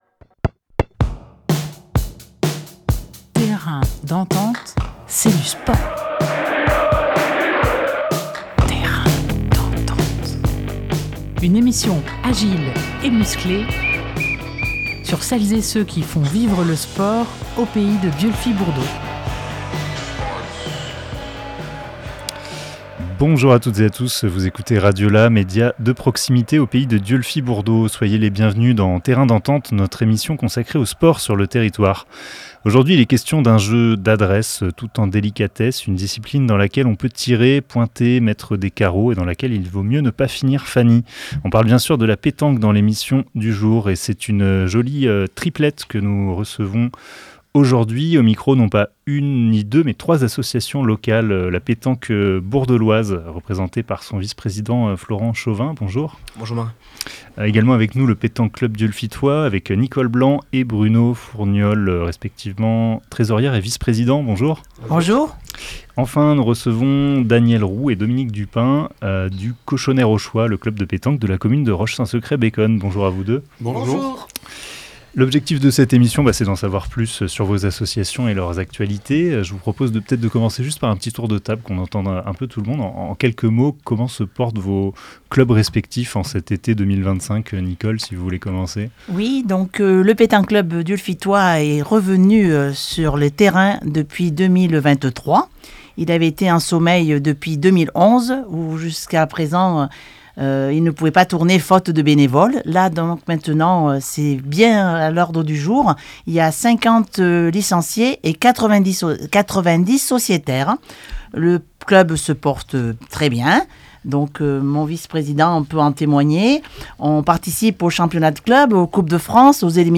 22 juillet 2025 11:39 | Interview, Terrain d'entente
Pour l’occasion, nous avons le plaisir de recevoir trois associations locales animées par une même passion : la Pétanque Bourdeloise, Le Pétanc’Club Dieulefitois et le Cochonnet Rochois.